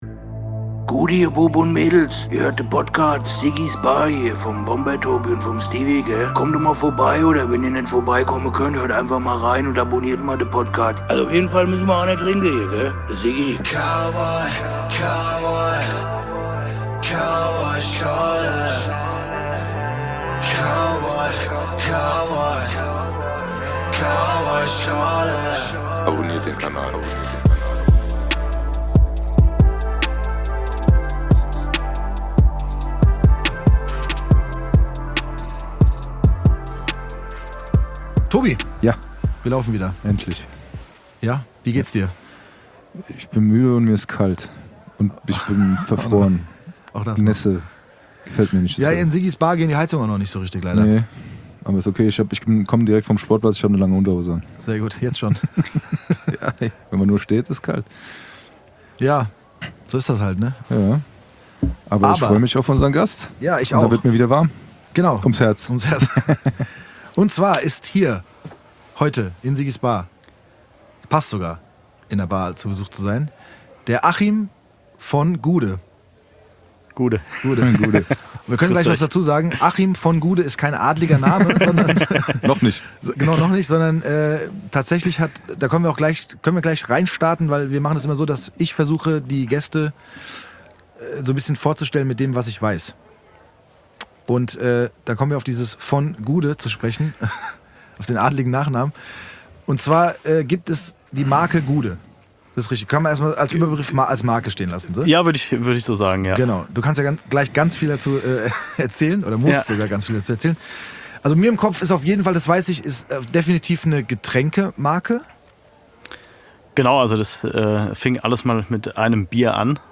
Ein interessantes Gespräch am Stammtisch über Erfüllung eines Traums und die Kombination von Professionalität und Emotionen.